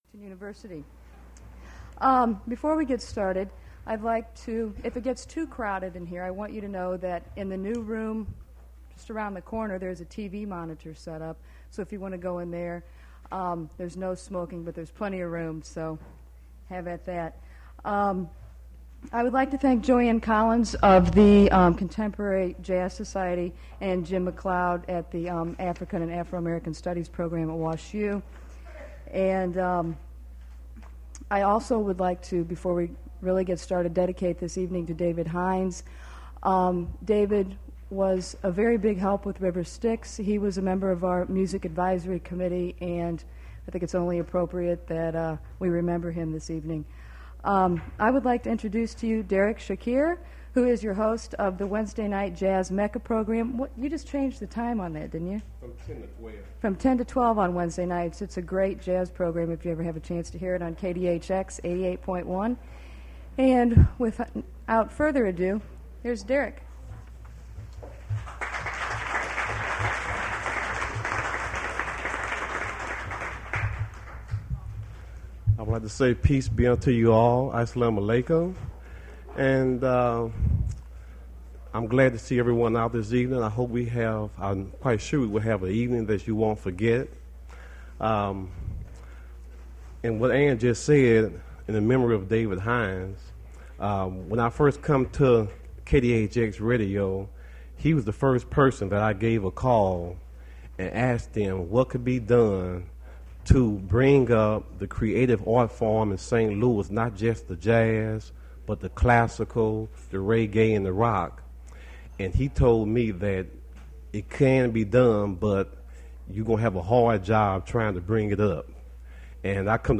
Attributes Attribute Name Values Description Oliver Lake poetry reading at Duff's Restaurant.
mp3 edited access file was created from unedited access file which was sourced from preservation WAV file that was generated from original audio cassette.
The volume of poem recording is too small. 17:13 is a piece of music after the poem reading, cannot tell whether this is a part of the poem reading, so I keep it.